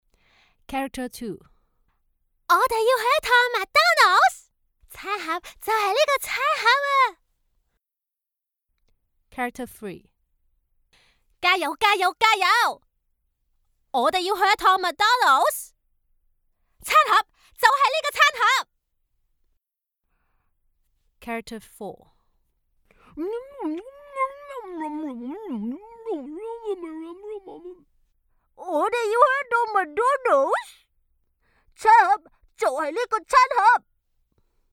Female
Character / Cartoon
Different Cartoon Voice Sample